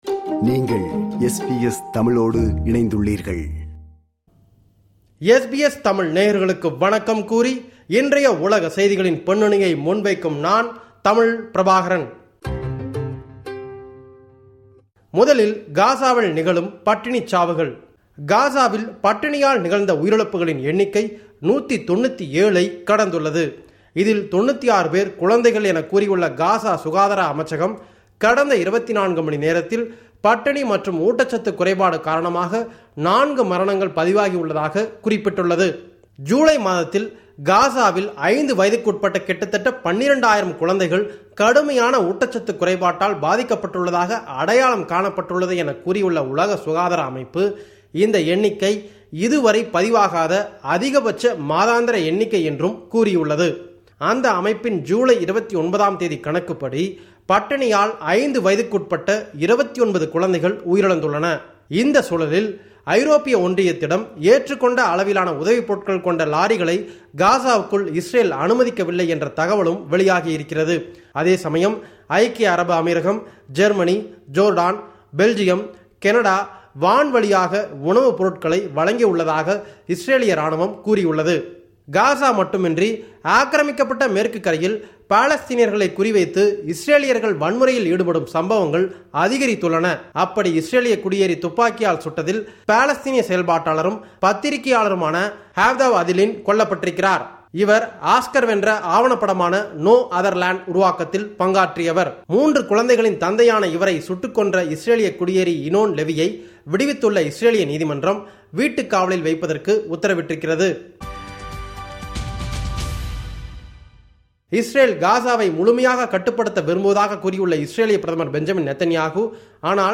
இந்த வார உலக செய்திகளின் தொகுப்பு